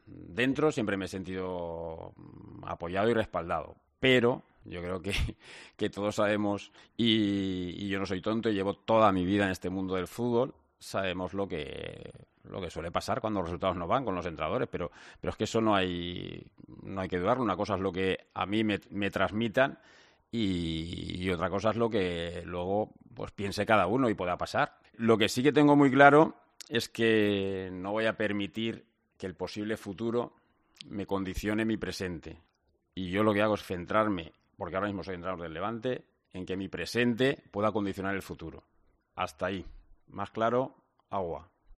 AUDIO. Paco López habla de su futuro